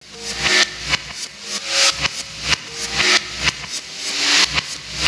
Black Hole Beat 03.wav